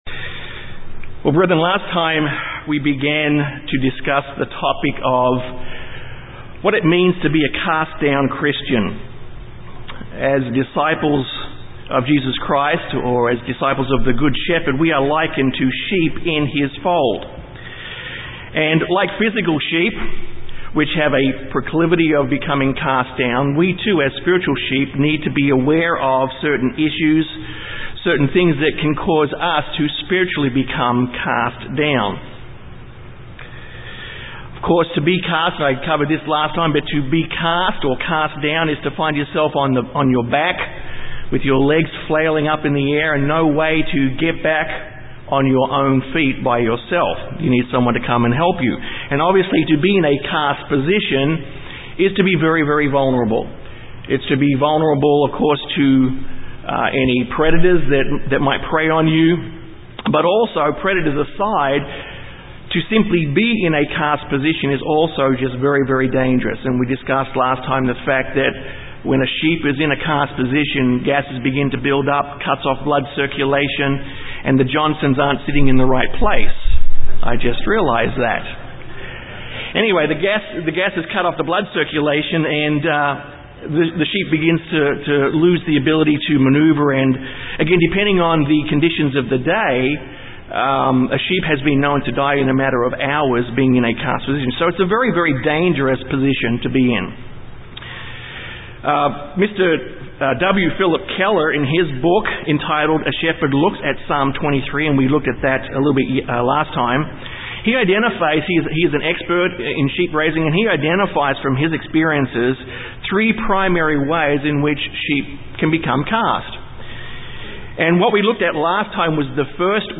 This sermon is the second of three addressing the concept of being "Cast Down".
Given in San Antonio, TX